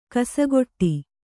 ♪ kasagoṭṭi